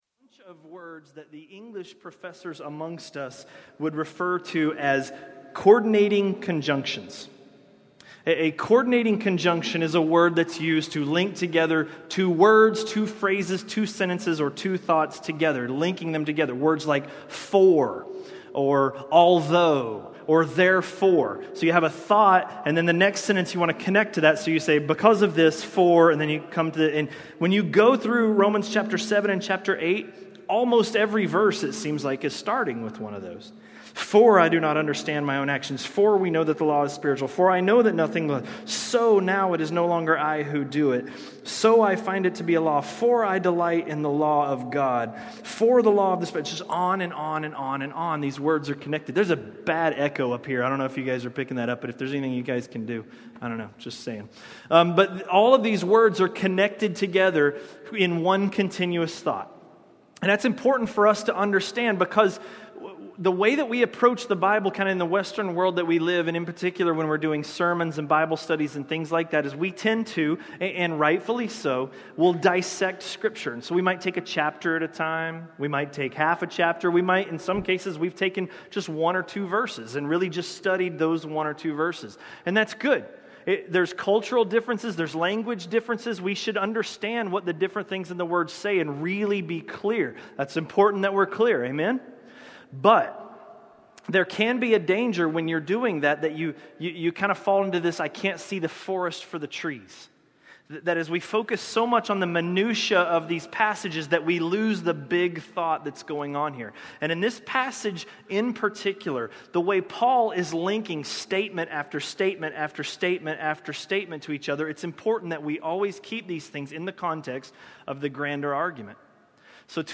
A message from the series "Romans." Romans 8:5–8:11